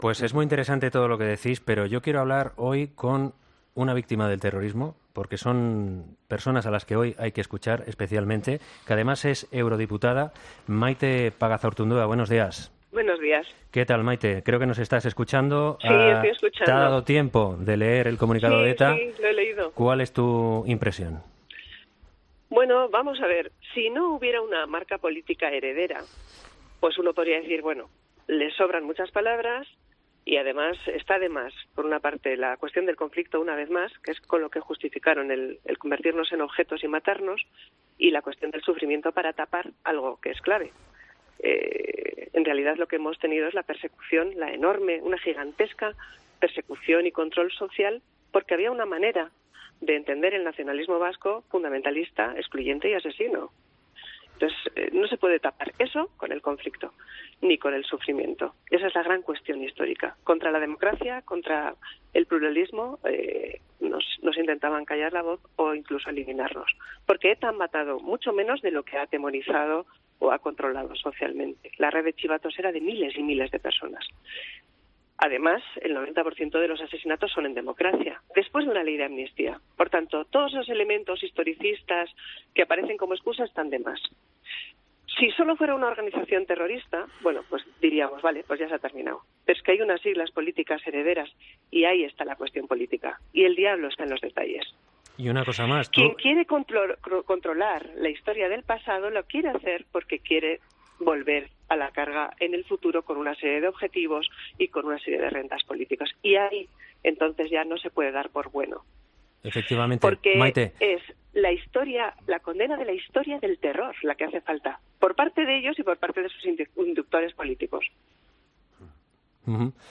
Entrevista a la eurodiputada Maite Pagazaurtundúa